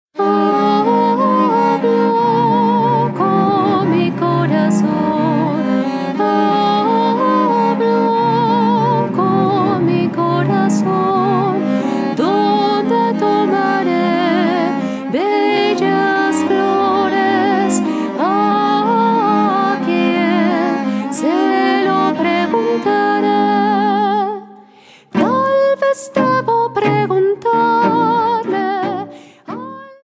GRABADO EN planet estudio, Blizz producciónes
SOPRANO
VIOLONCELLO SOLO Y ENSAMBLES